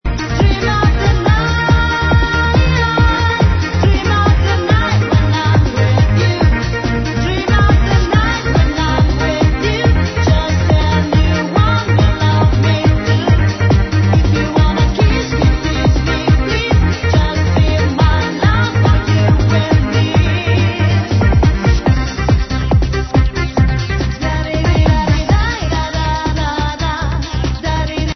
Club version